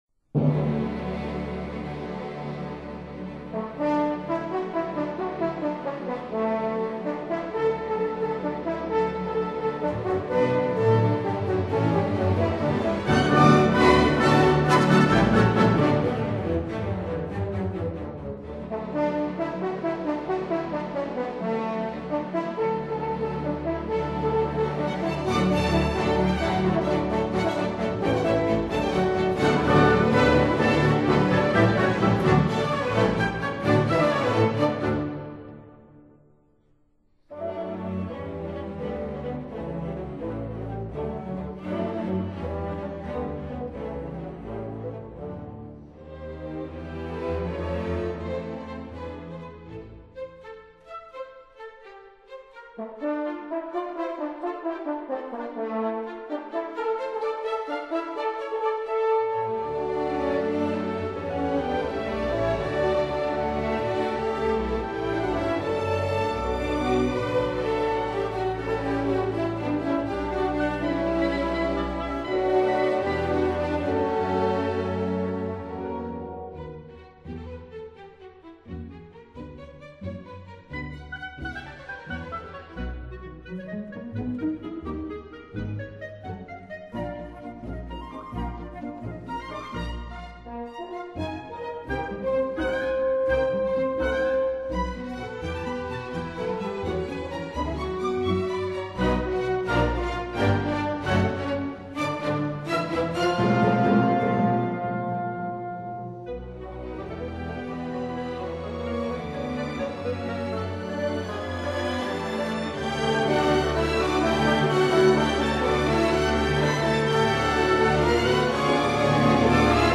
for strings and harp
piano
harp